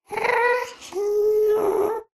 moan6.ogg